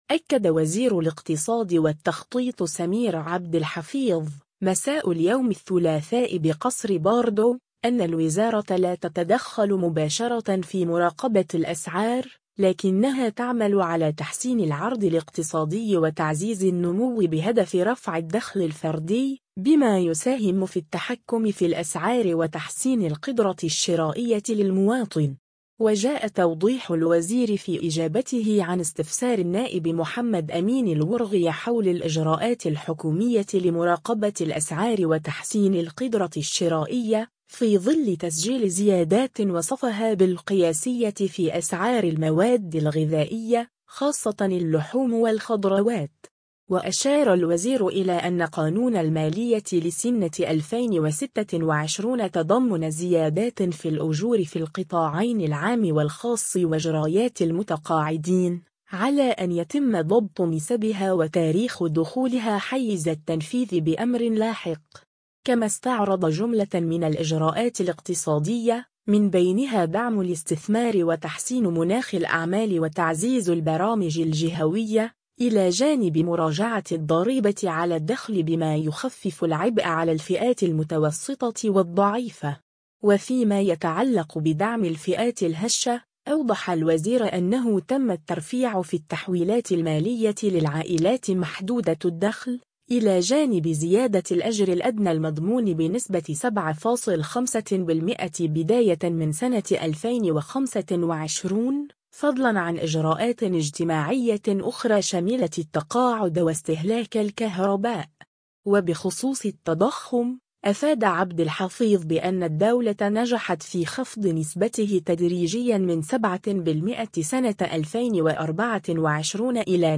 أكد وزير الاقتصاد والتخطيط سمير عبد الحفيظ، مساء اليوم الثلاثاء بقصر باردو، أن الوزارة لا تتدخل مباشرة في مراقبة الأسعار، لكنها تعمل على تحسين العرض الاقتصادي وتعزيز النمو بهدف رفع الدخل الفردي، بما يساهم في التحكم في الأسعار وتحسين القدرة الشرائية للمواطن.